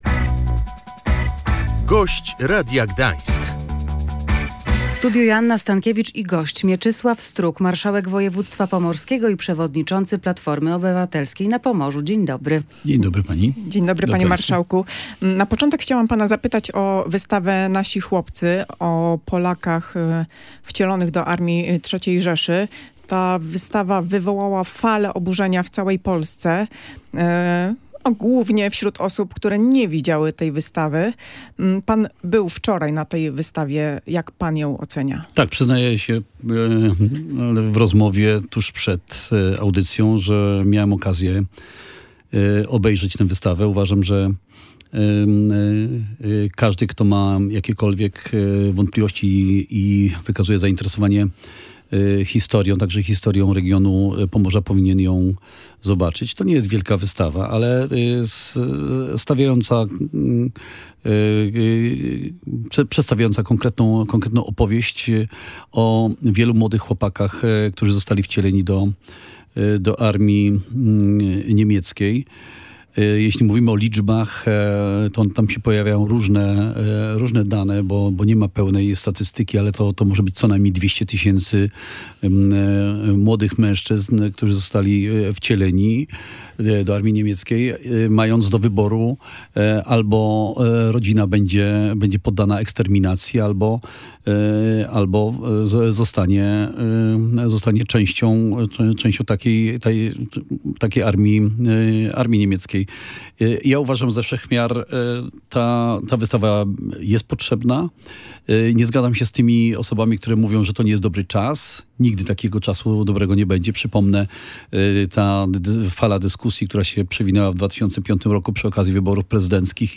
Nie zgadzamy się na ingerencję polityczną w wydarzenia kulturalne – wskazał na antenie Radia Gdańsk, w kontekście wystawy „Nasi chłopcy”, marszałek województwa pomorskiego i przewodniczący Platformy Obywatelskiej na Pomorzu Mieczysław Struk.